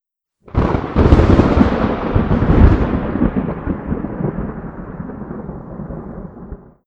thunder1.wav